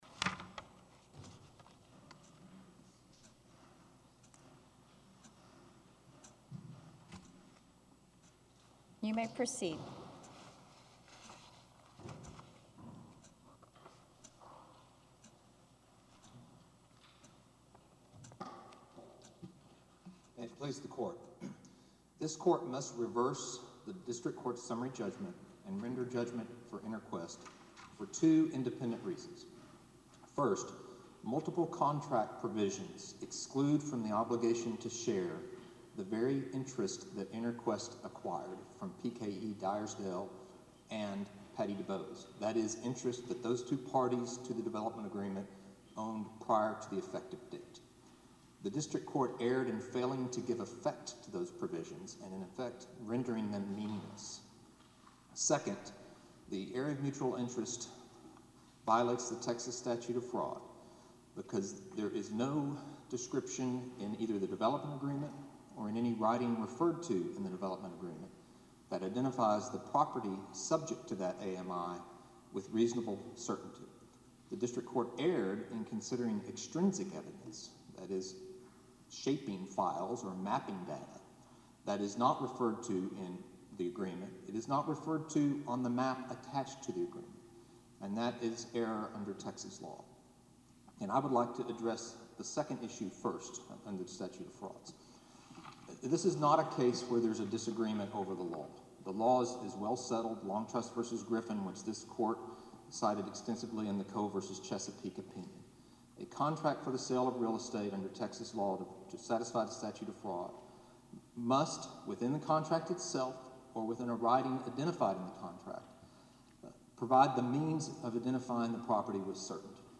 A recording of the oral argument can be found here.